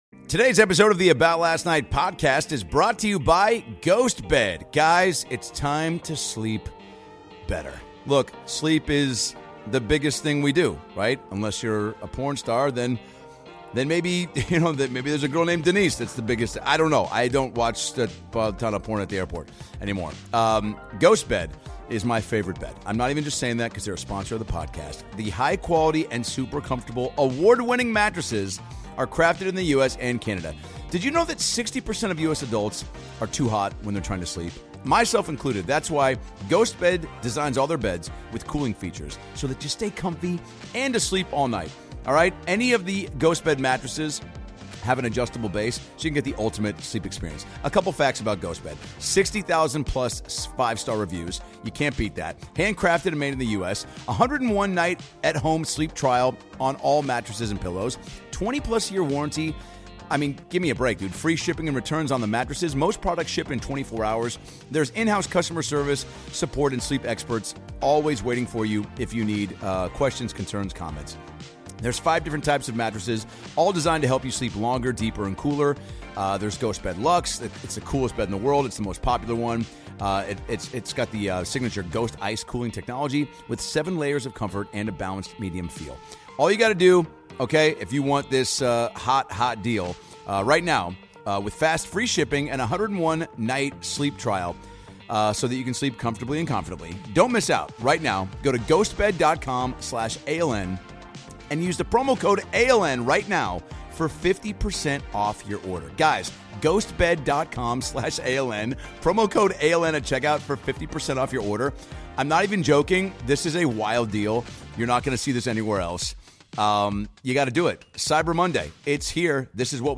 Dr Phil LIVE! is coming out of Capital One Hall in D.C. with the "Are You Garbage" guys and Jim Norton!